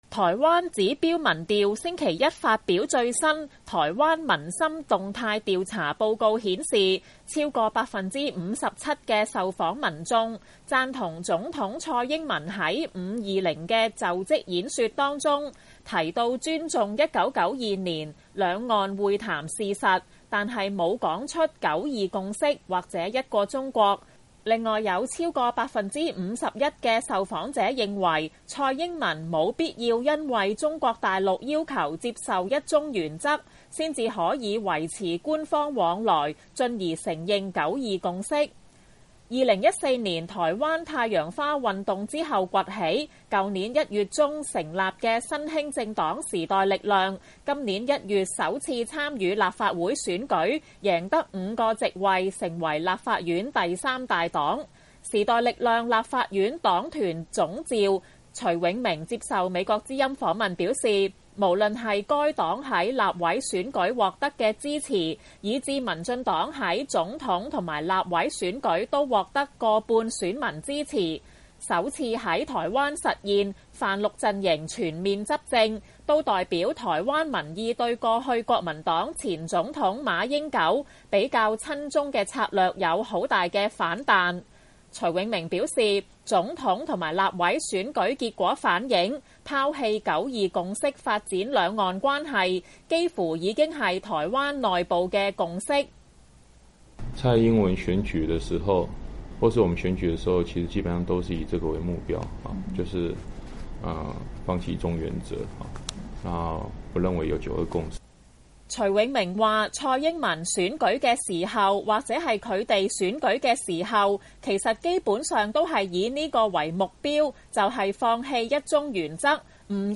台灣總統蔡英文就職十日，如何處理兩岸及台港關係備受關注。新崛起的政黨時代力量立法院黨團總召徐永明接受美國之音訪問表示，總統大選結果反映台灣民意大部份已經拋棄九二共識，他建議台灣新政府加強台港關係，可以考慮修改《兩岸人民關係條例》，將香港與中國大陸作出區隔，針對香港特別立法。